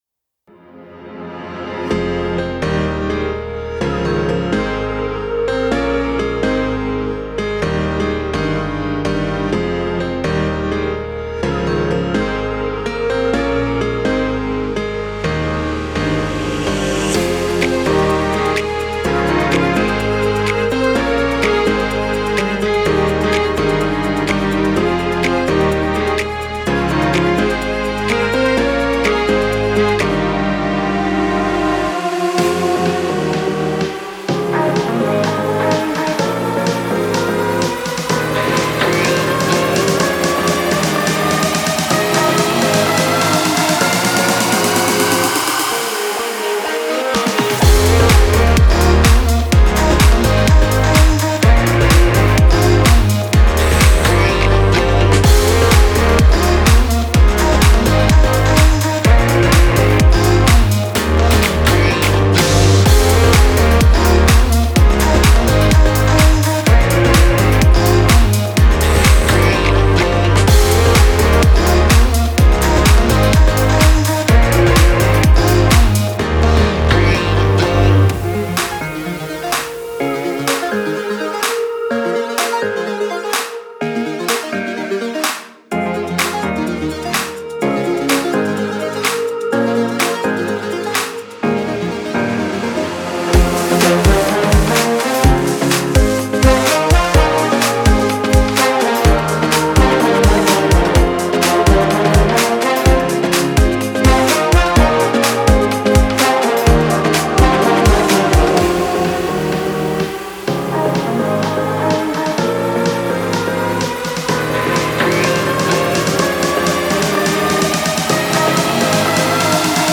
это мощная поп-баллада, наполненная эмоциями и искренностью.